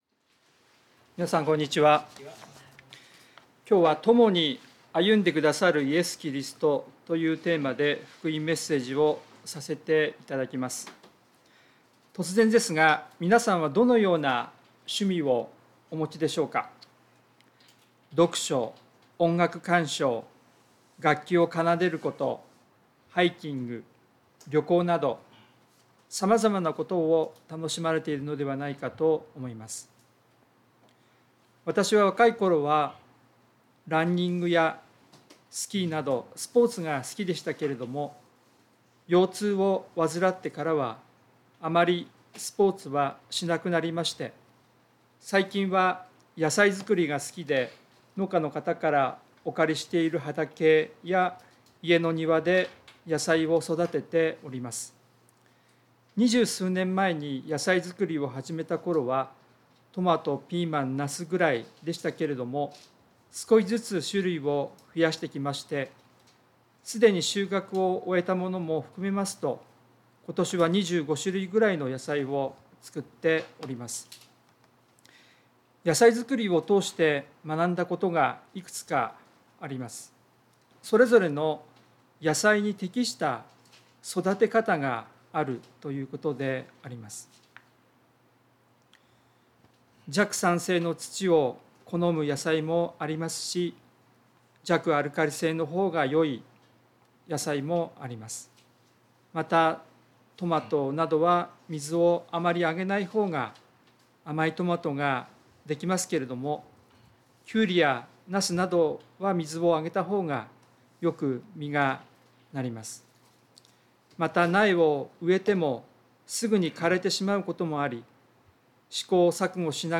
聖書メッセージ No.278